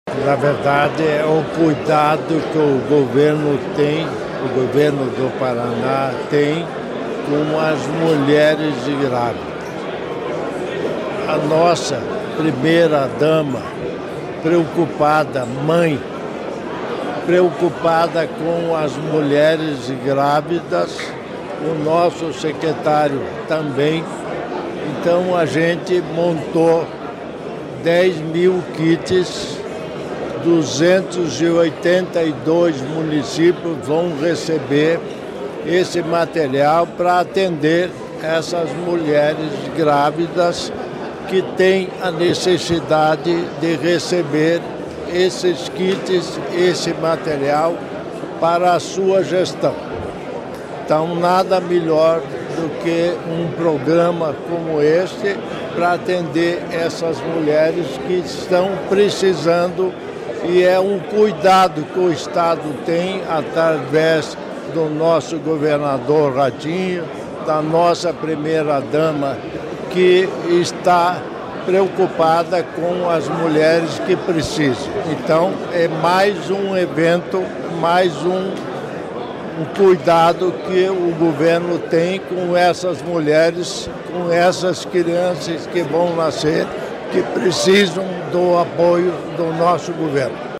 Sonora do governador em exercício Darci Piana sobre o programa Nascer Bem Paraná